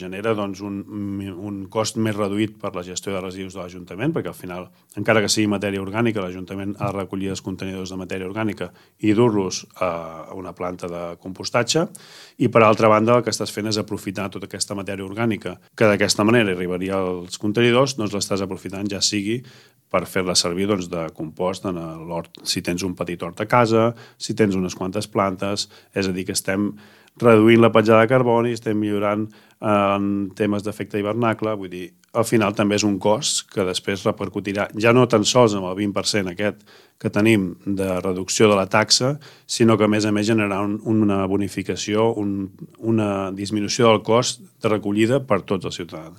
El regidor de Medi Ambient, Sergi Masó, destaca que els avantatges d’aquesta acció no només són individuals, sinó col·lectius.